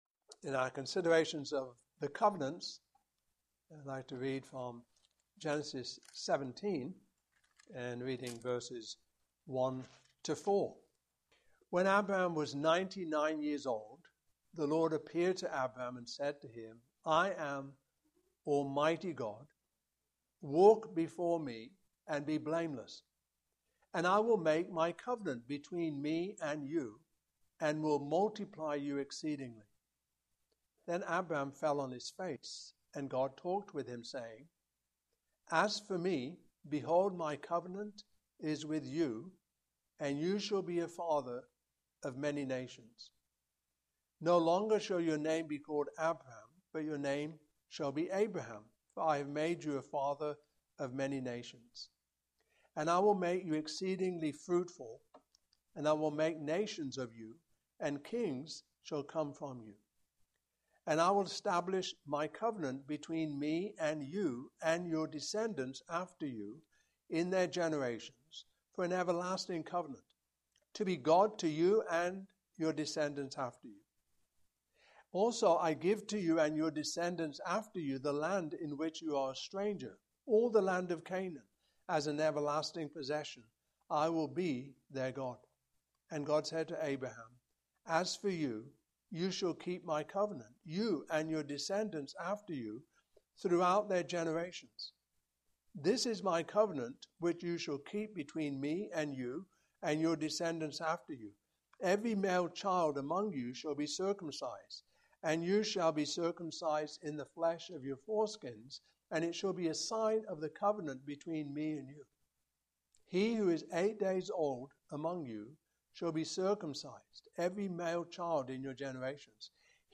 Passage: Genesis 17:1-14 Service Type: Morning Service